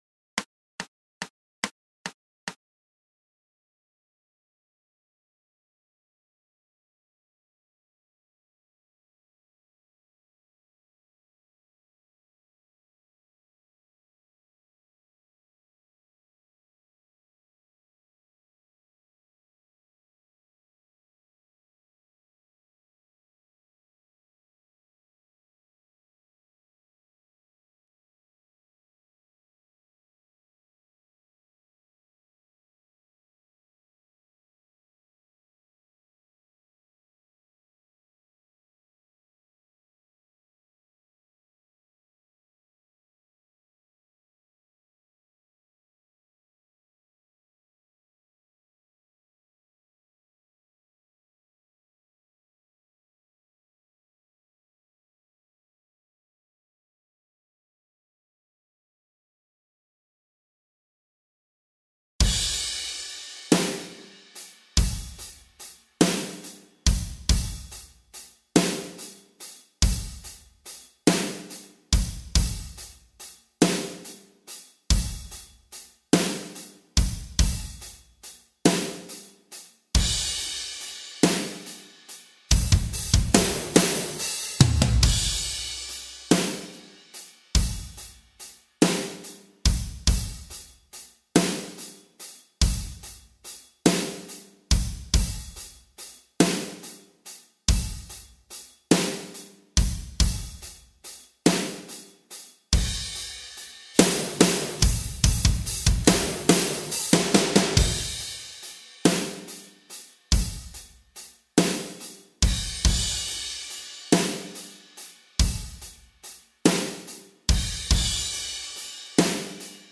BATERÍA (Descargar)